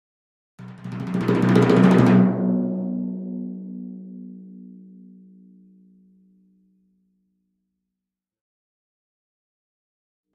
Timpani, (Hands), Speedy Crescendo, Type 2 - Medium Tone